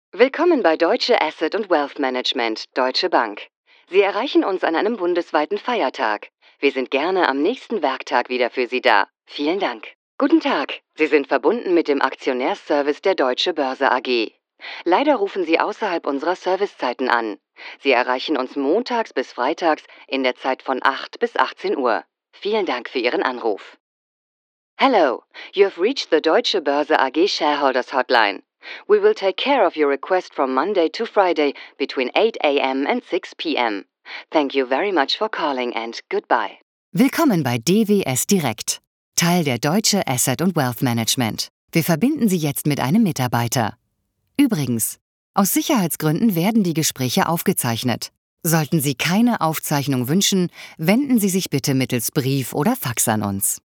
seit über 20 Jahren Profi-Sprecherin, bekannte Stimme aus Funk und TV, Werbung und Service-Telefonie, volle, warme, weibliche Stimme, sehr wandelbar von werblich über seriös/ernst zu informativ und freundlich
Kein Dialekt
Sprechprobe: eLearning (Muttersprache):